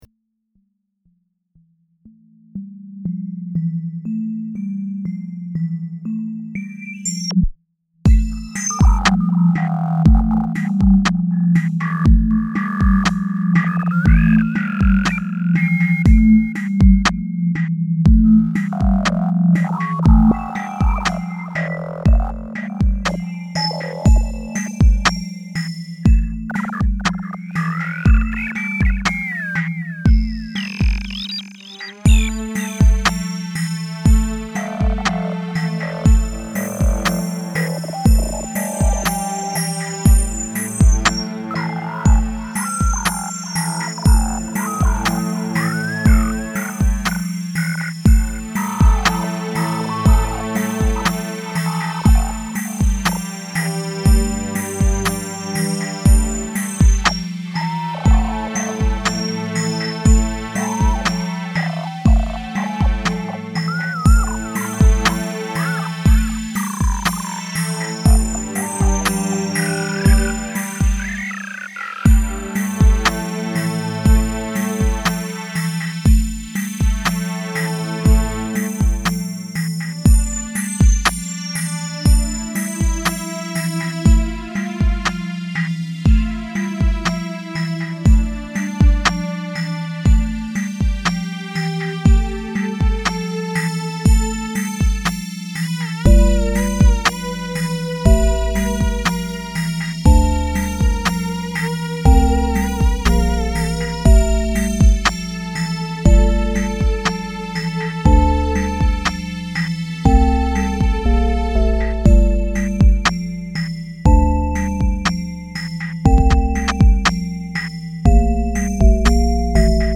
electronic ambient music
ambient music